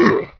painshrt.wav